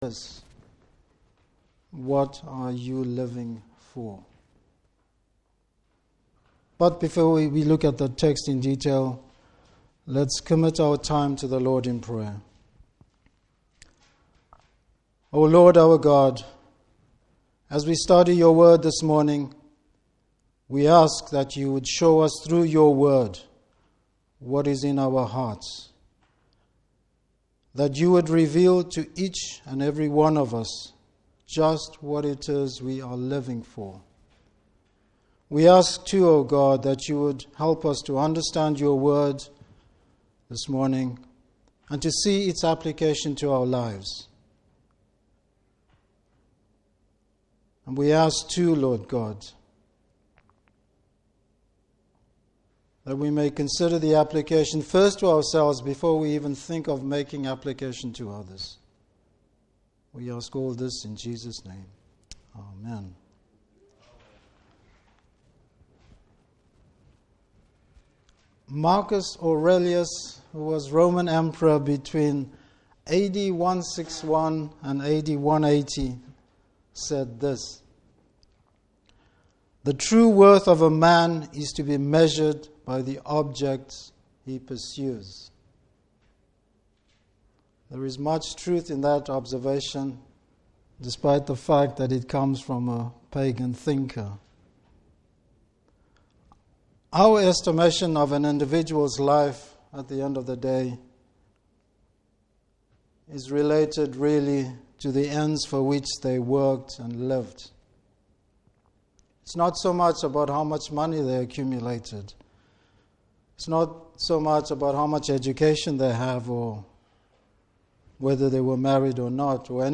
Service Type: Morning Service What does it mean to live for Christ?